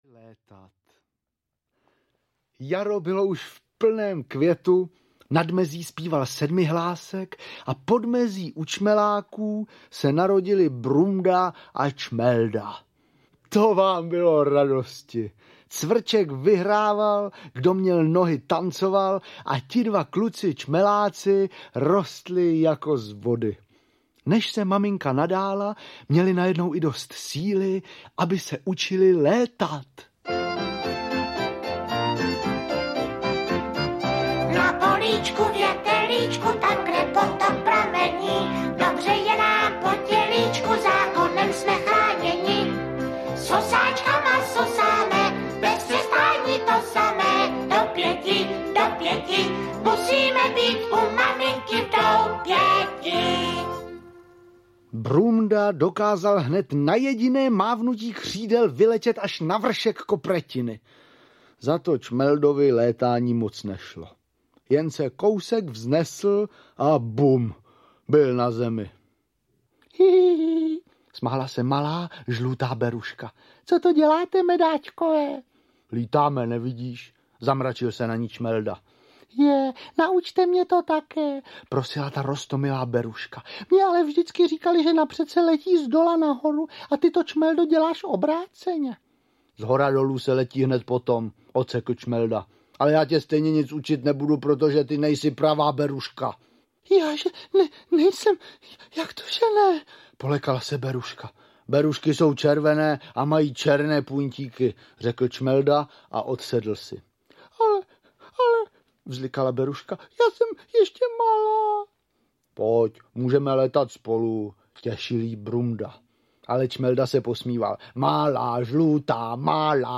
Večerníčkovy pohádky do ouška audiokniha
Ukázka z knihy
Výběr z oblíbených pohádek představuje slavné tituly, které založily věhlas televizního Večerníčka. Zvukové verze těchto pohádek jsou stejně populární, jako jejich animované předlohy.